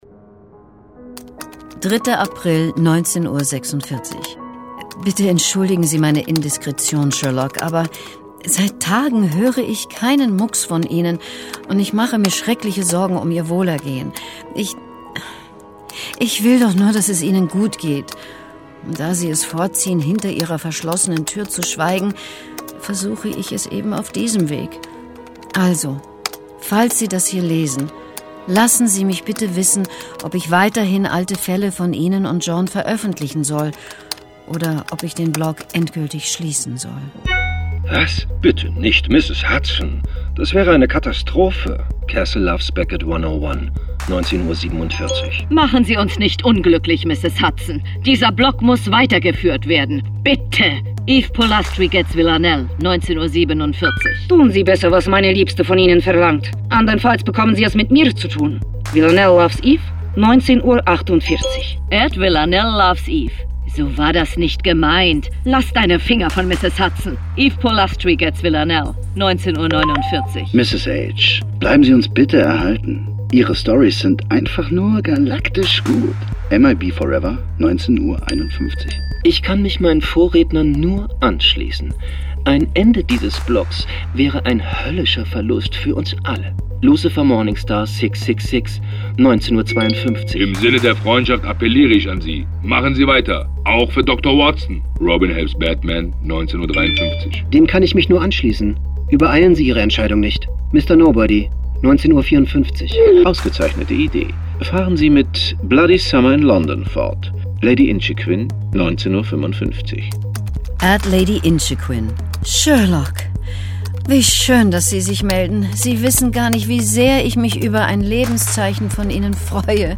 Hörspiel mit Johann von Bülow, Florian Lukas, Stefan Kaminski u.v.a. (2 CDs)
»Das Ensemble ist hervorragend, die Hörspiele aufwendig und sehr ansprechend produziert. Hohes Tempo, emotionale Musik und eine gute Prise Humor tun ihr übriges.« SWR2